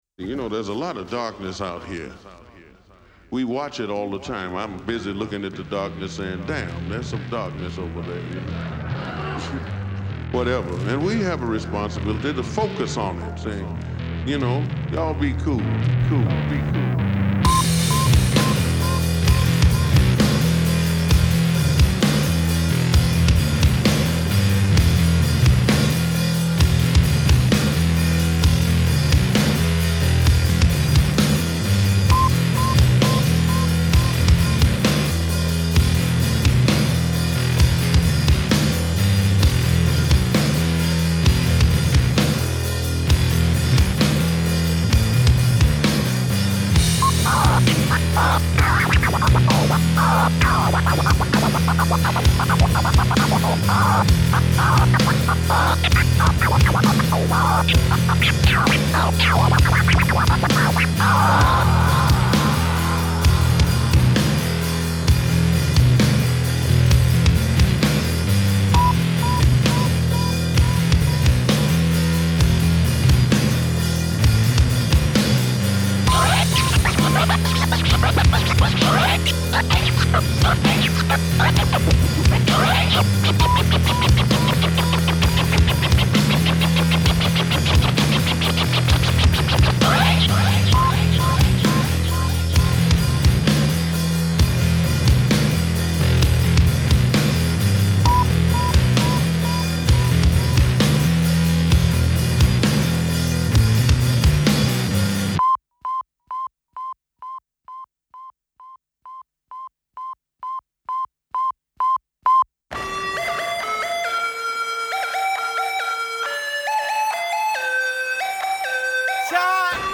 ブラック&ハードコア・スタイル！新譜しばりの選曲でMIXした要注目の一発、お聞き漏れなく・・・...